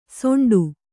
♪ soṇḍu